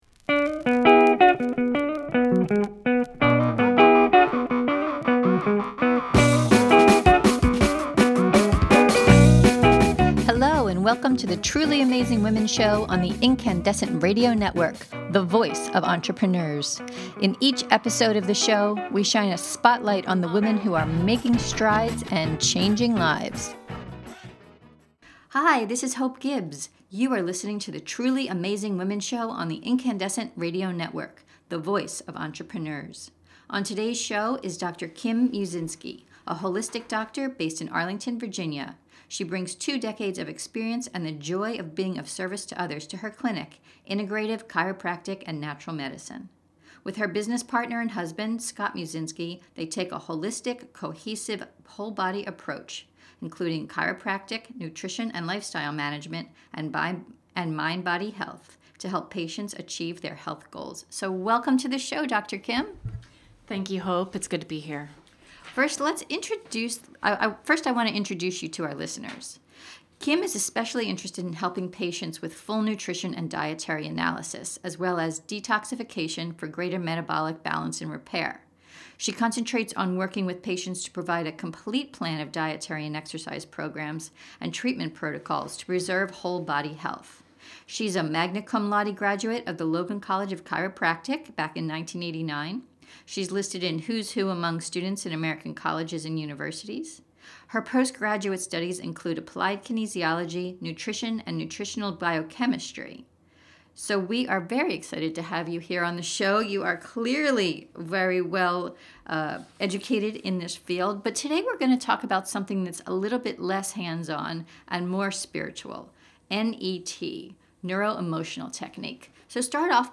In this podcast interview you’ll learn: How the Neuro Emotional Technique works — including the science behind the therapy. Why a simple muscle test reveals things you didn’t know were bugging you.